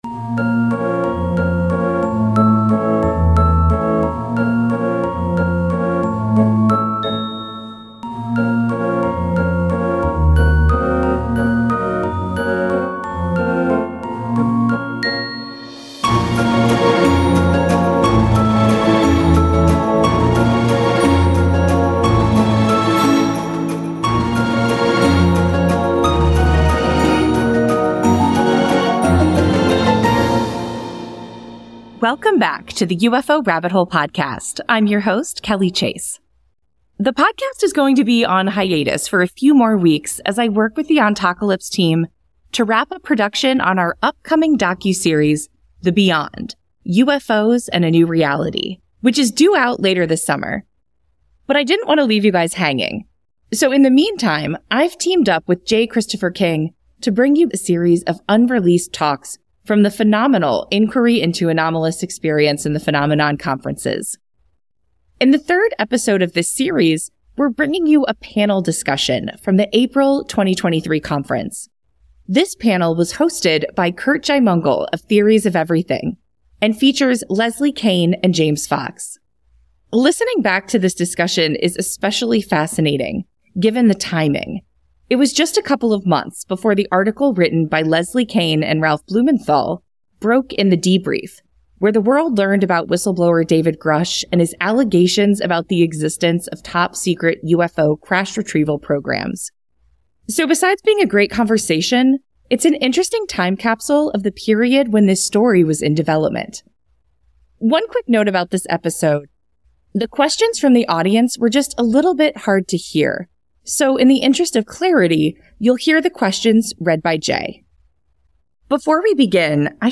In this never-before released panel talk from the Inquiry into Anomalous Experience & the Phenomenon conference from April 2023